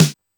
fhg_snare_707.wav